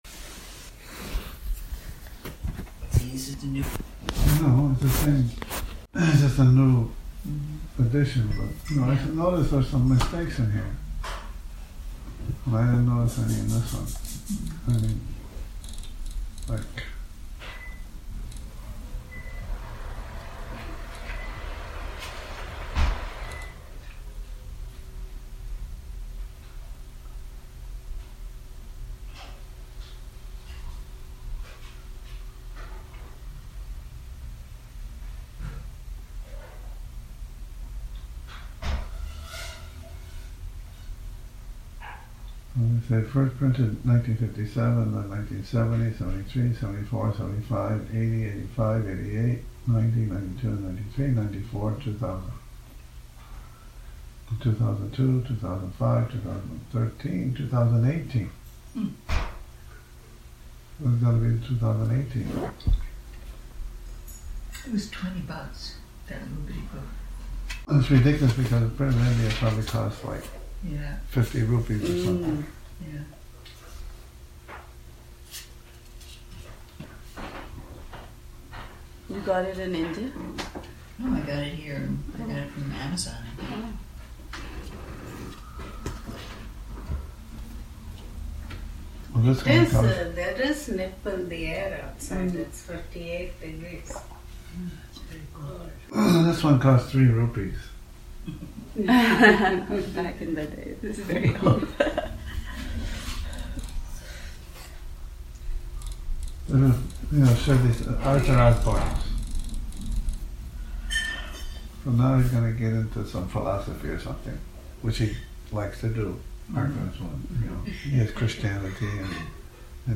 Morning Reading, 25 Sep 2019
a reading from "The Incredible Sai Baba" by Arthur Osborne, Morning Reading, 25 Sep 2019